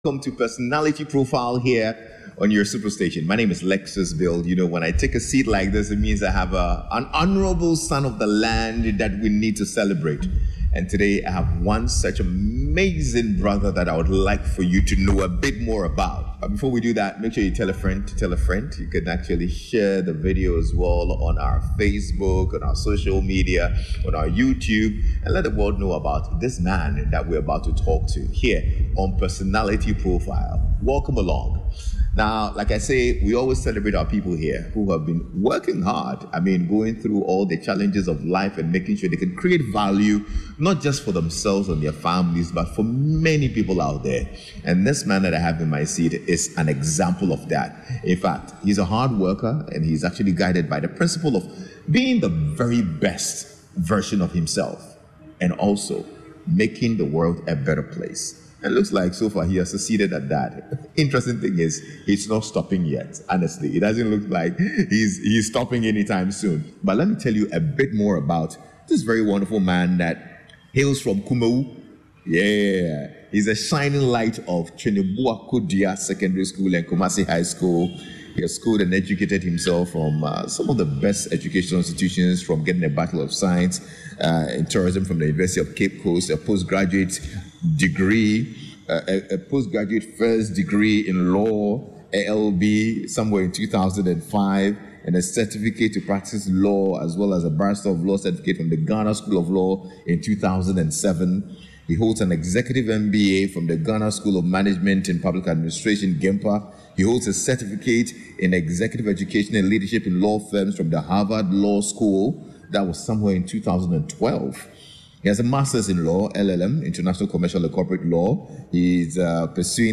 Conversational talk show with important personalities celebrating their lives and achievements